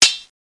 Sword2.mp3